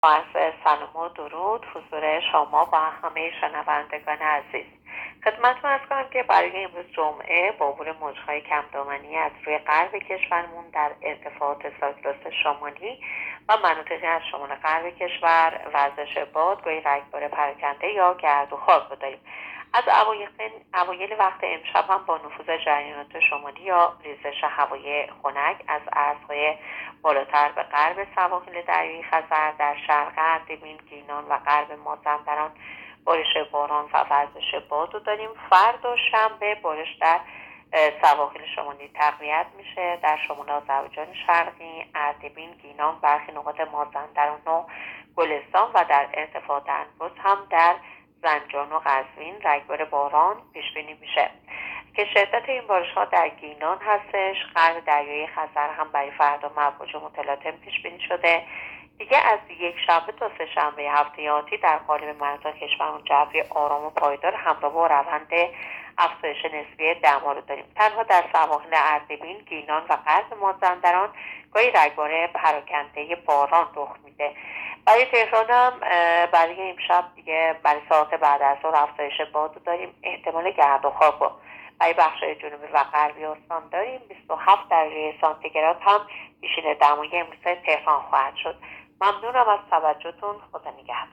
گزارش رادیو اینترنتی پایگاه‌ خبری از آخرین وضعیت آب‌وهوای ۴ مهر؛